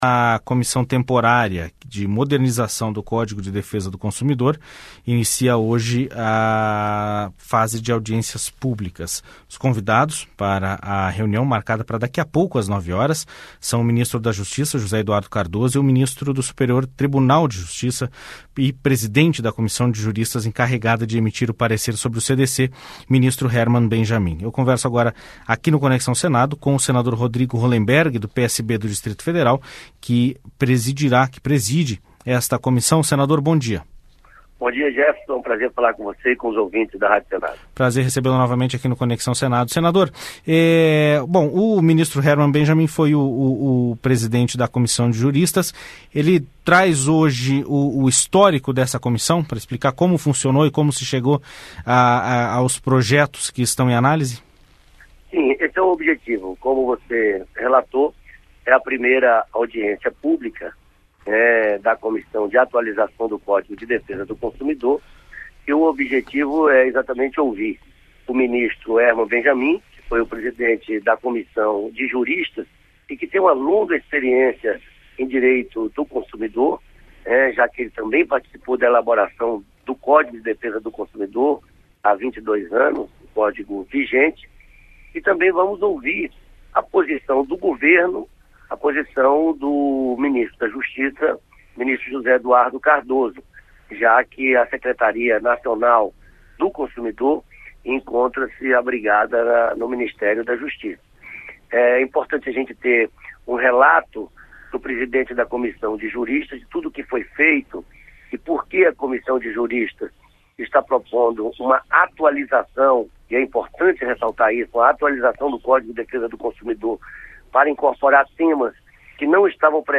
Entrevista com o presidente da Comissão Temporária de Reforma do Código de Defesa do Consumidor.